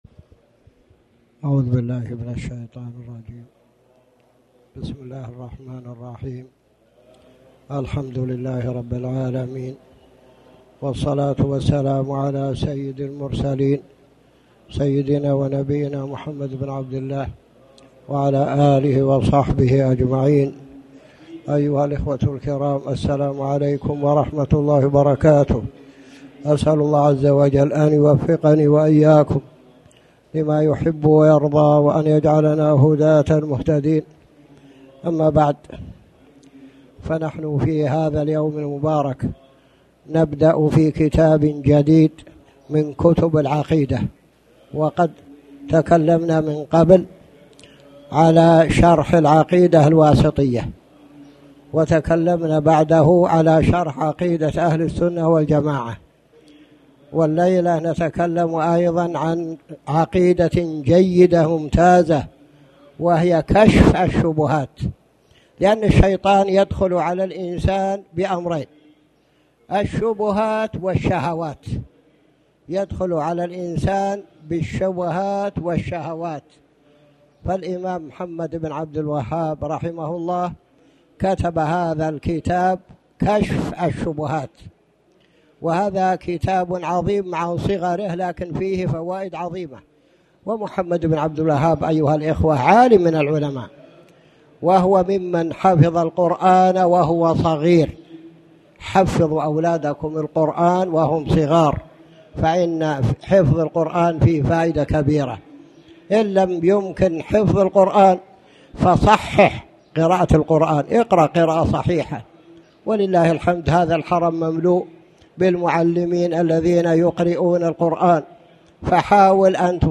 تاريخ النشر ١ جمادى الآخرة ١٤٣٩ هـ المكان: المسجد الحرام الشيخ